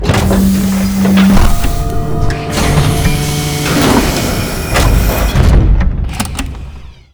StationAirlock.wav